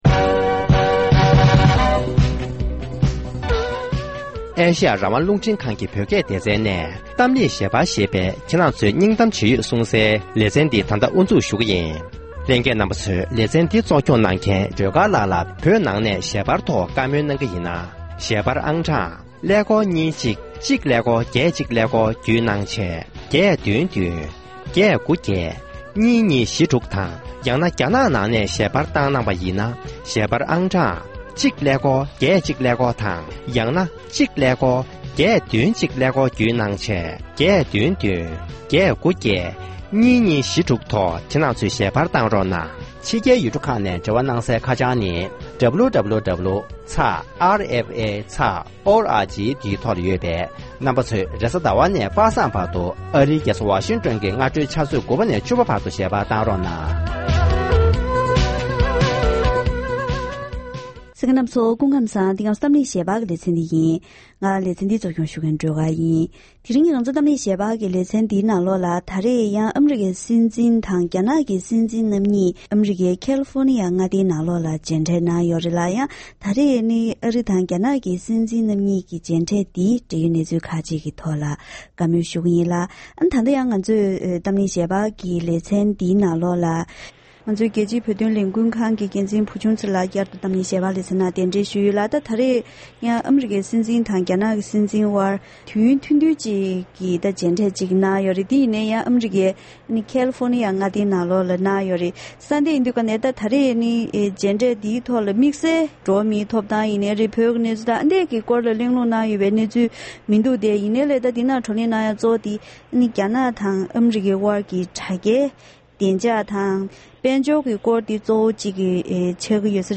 ༄༅༎དེ་རིང་གི་གཏམ་གླེང་ཞལ་པར་ལེ་ཚན་ནང་ད་རེས་ཉེ་ཆར་ཨ་རིའི་སྲིད་འཛིན་དང་རྒྱ་ནག་གི་སྲིད་འཛིན་རྣམ་གཉིས་མཇལ་འཕྲད་གནང་ནས་དྲ་རྒྱའི་བདེ་འཇགས་དང་དཔལ་འབྱོར་གྱི་གནད་དོན་སོགས་རྒྱ་ནག་དང་འབྲེལ་བ་ཆགས་པའི་གནད་དོན་ཁག་ཅིག་གི་ཐོག་བགྲོ་གླེང་ཞུས་པ་ཞིག་གསན་རོགས་གནང་།།